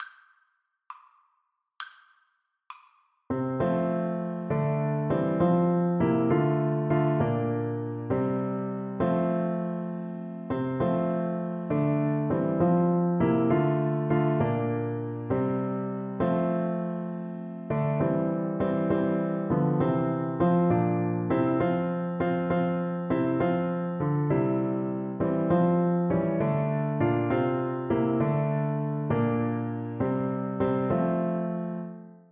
6/8 (View more 6/8 Music)
C6-C7
Christmas (View more Christmas Flute Music)